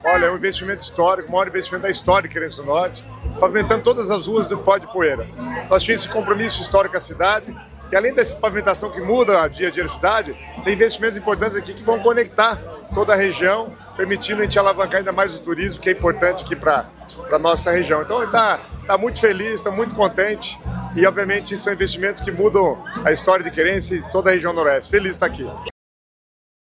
Sonora do secretário das Cidades, Guto Silva, sobre o anúncio de quase R$ 120 milhões em investimentos para Querência do Norte